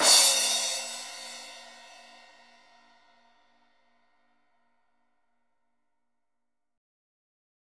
CRASH 2.wav